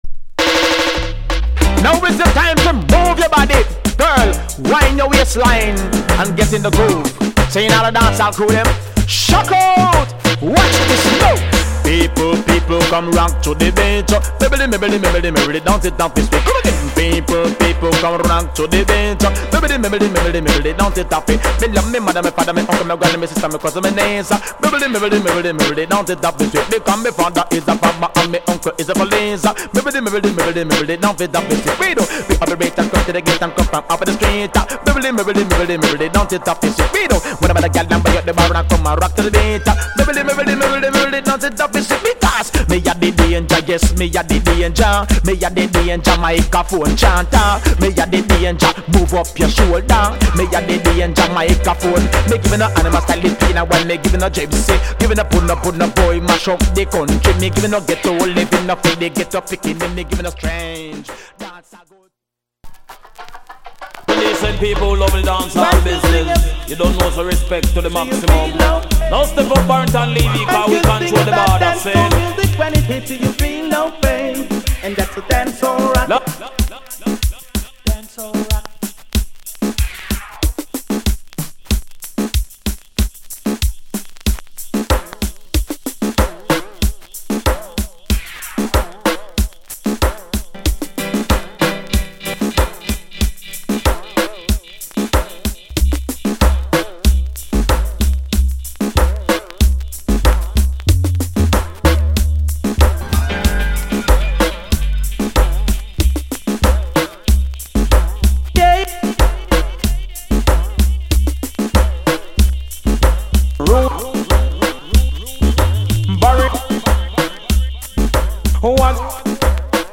Genre ReggaeAfter90s
Male DJ